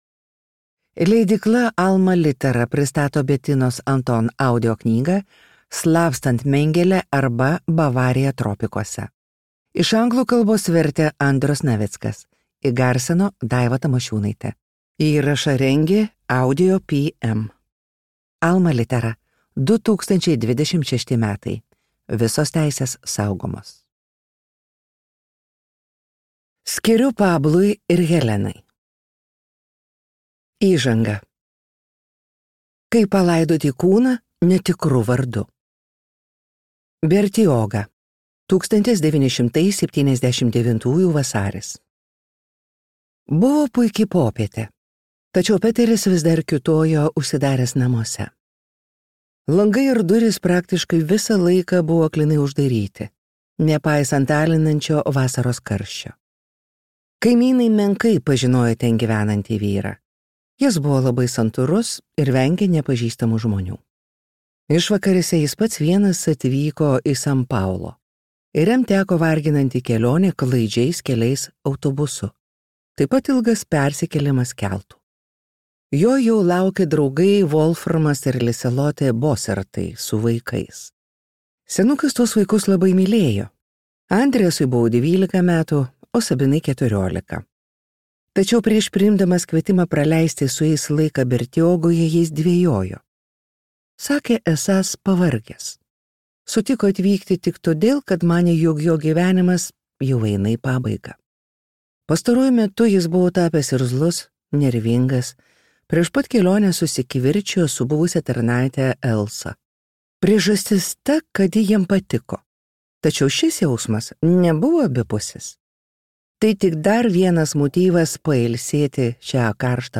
Slapstant Mengelę arba Bavarija tropikuose | Audioknygos | baltos lankos